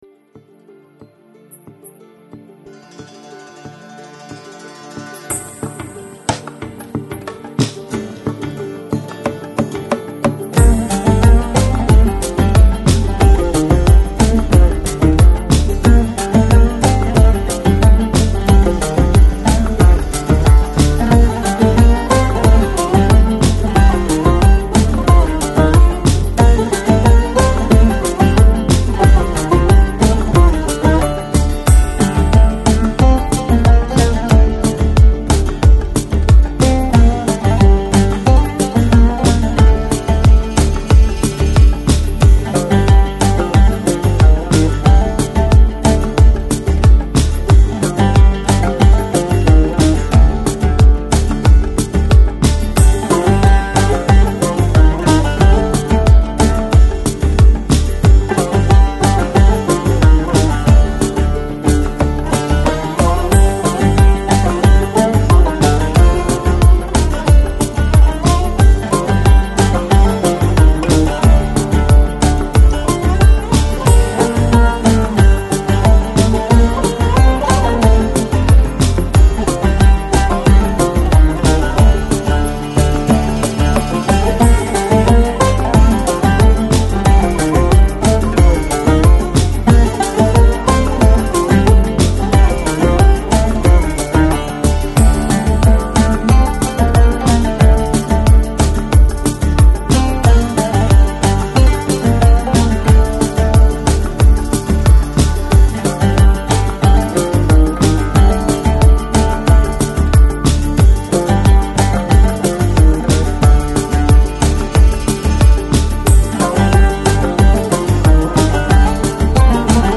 Chill Out, Downtempo, Organic House, Ethnic, World